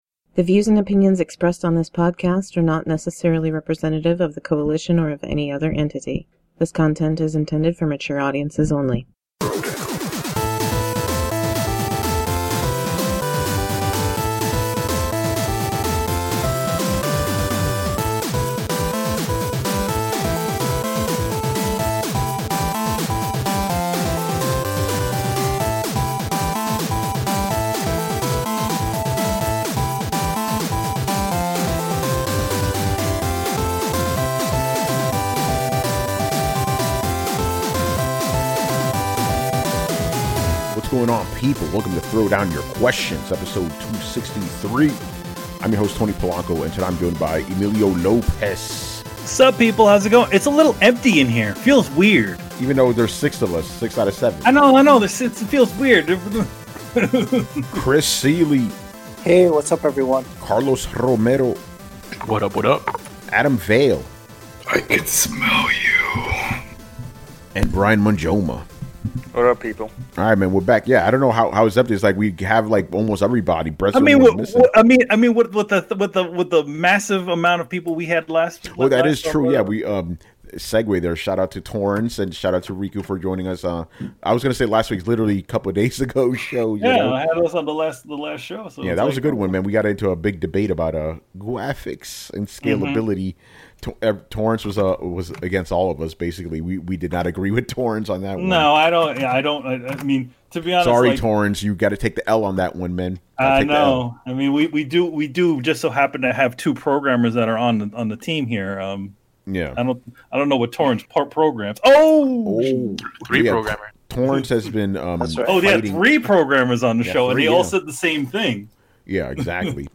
for our intro and outro music